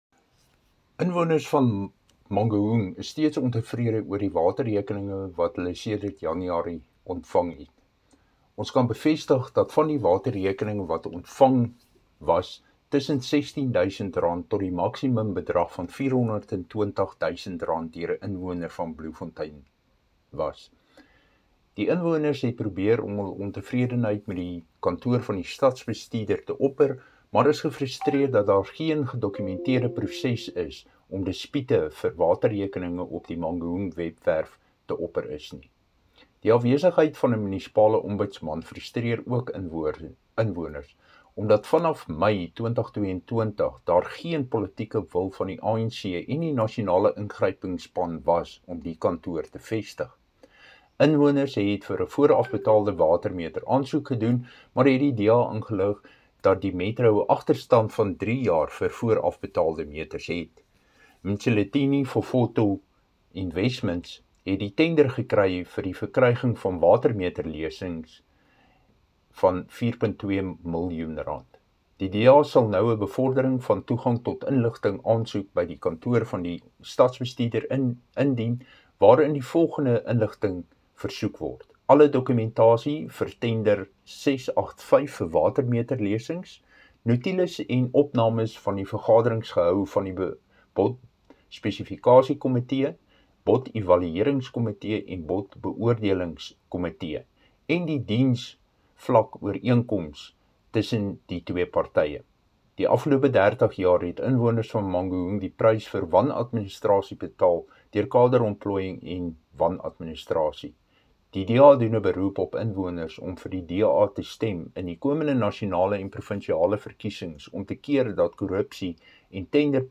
Afrikaans soundbites by Cllr Dirk Kotze and Sesotho by Cllr David Masoeu.